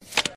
discard.mp3